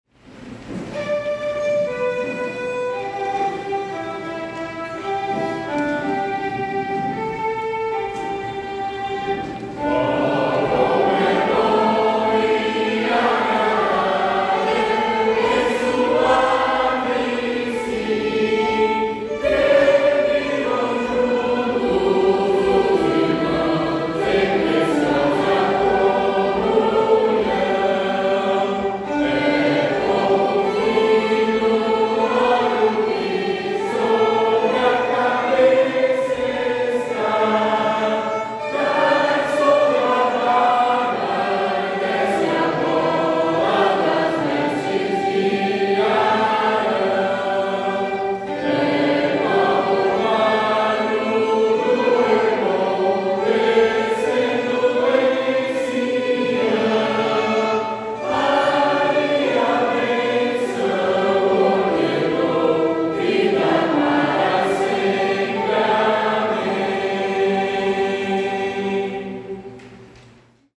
Salmos metrificados para o canto em língua portuguesa
salmo_133B_cantado.mp3